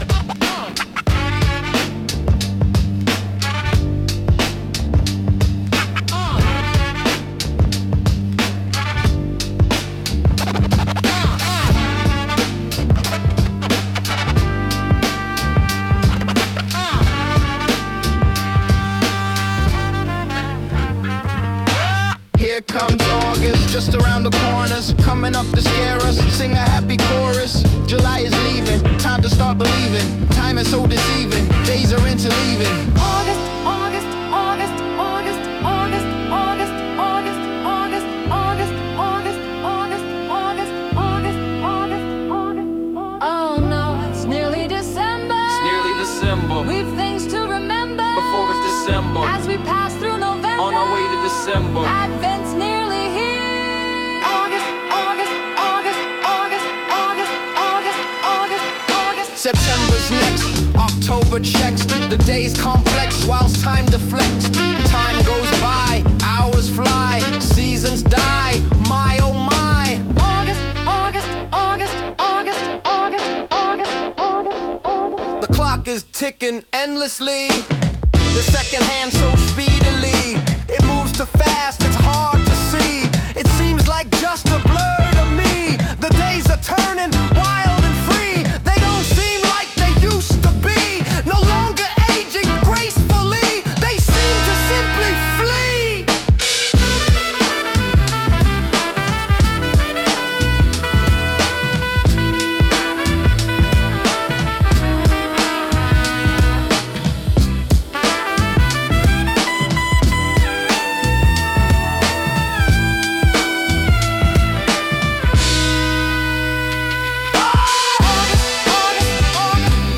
Sung by Suno
August_Incoming_(Remix)-3_mp3.mp3